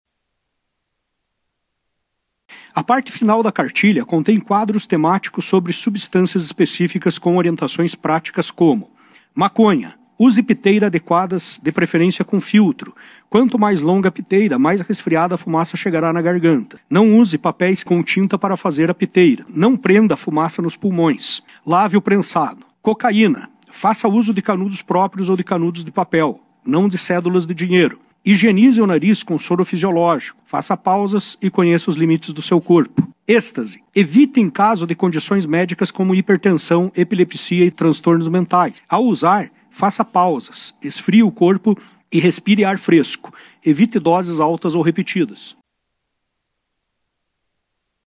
A apresentação da cartilha gerou a denúncia por quebra de decoro parlamentar, feita pelos vereadores Da Costa (União Brasil) e Bruno Secco (PMB), conforme explicado durante a reunião da comissão pelo vereador Olimpio Araújo Júnior (PL).